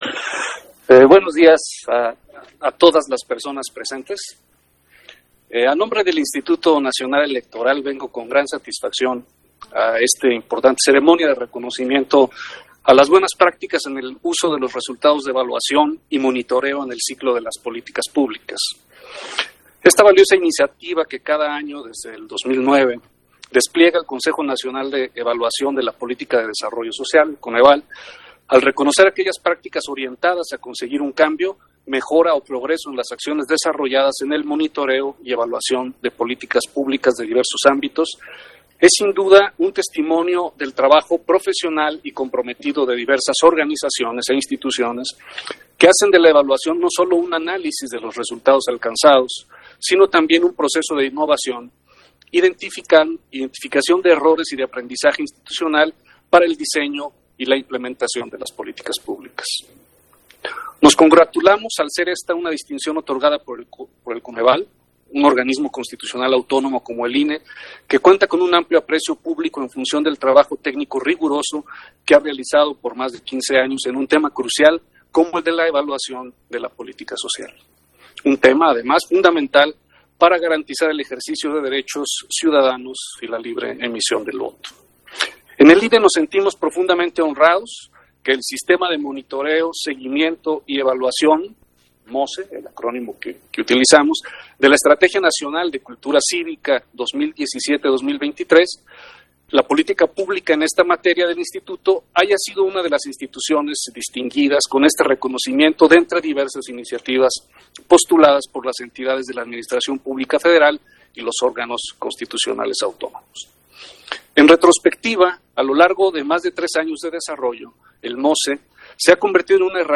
Intervención de Martín Faz, en el evento de reconocimiento a las buenas prácticas en el uso de los resultados de evaluación y monitoreo en el ciclo de políticas públicas 2022